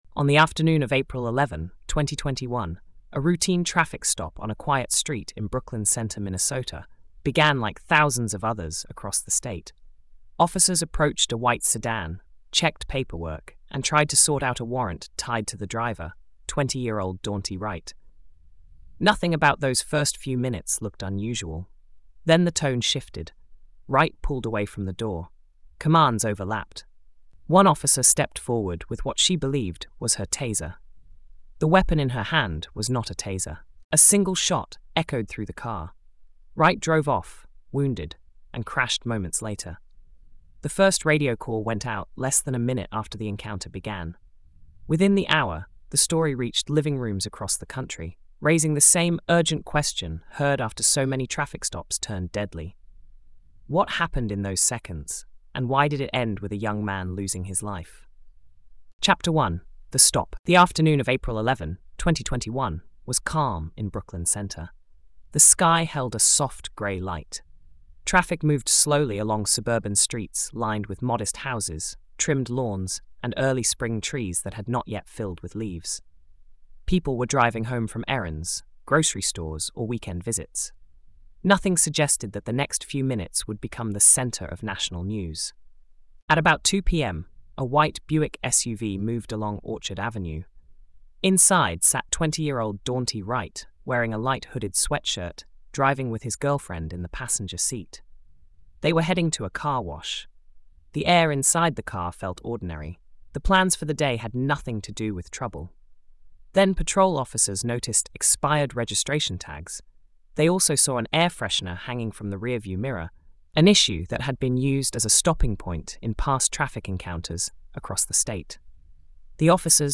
This documentary-style narrative reconstructs the fatal April 11, 2021 shooting of twenty-year-old Daunte Wright during a routine traffic stop in Brooklyn Center, Minnesota. Through calm, detailed storytelling, it follows the encounter from its ordinary beginning—expired tags, a brief conversation, standard procedures—to the sudden escalation at the driver’s door where Officer Kim Potter drew her handgun instead of her Taser and fired a single deadly shot. The story widens beyond the moment of the shooting, portraying Wright as a young father and son, Potter as a veteran officer with decades of service, and investigators as they piece together every second of the event through footage, evidence, and expert analysis.